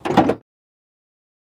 Cadillac 1964 Door Open